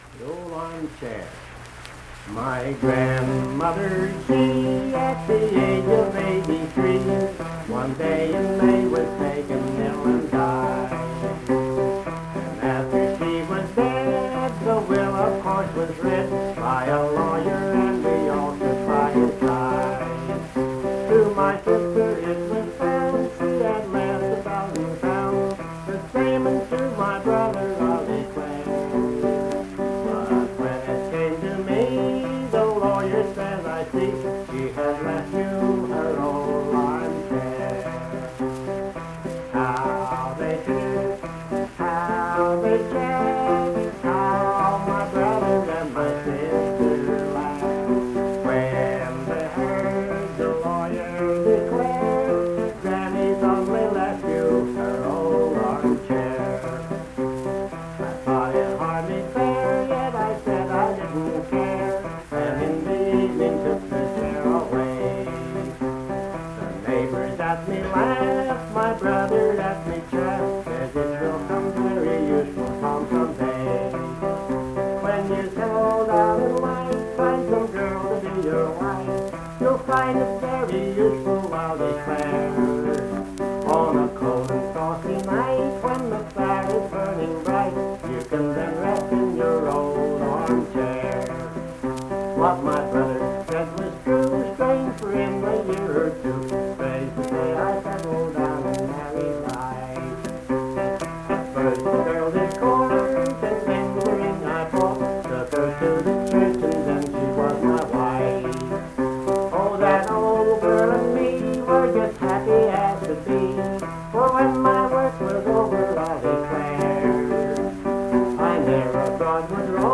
Accompanied vocal and guitar music
Verse-refrain 4(8)&R(4).
Performed in Hundred, Wetzel County, WV.
Voice (sung), Guitar